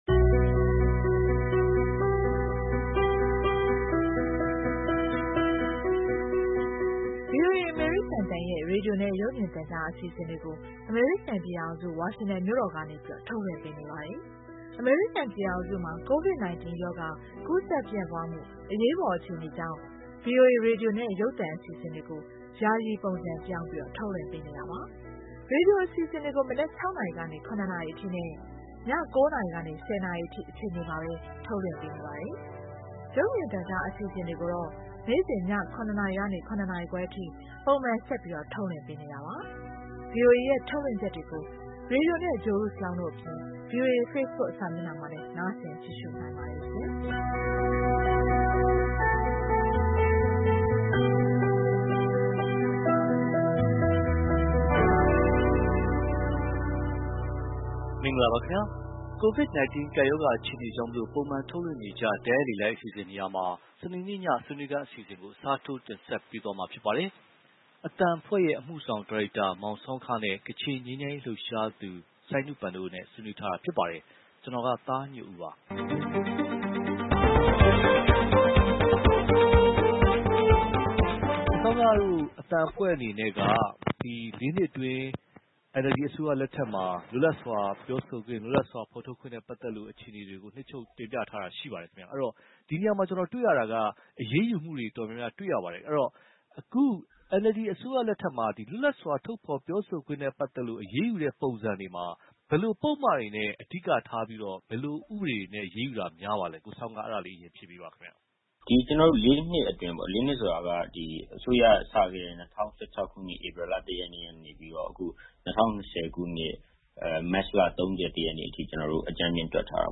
NLD အစိုးရလက်ထက် လွတ်လပ်စွာထုတ်ဖော်ပြောဆိုခွင့် (တိုက်ရိုက်လေလှိုင်း)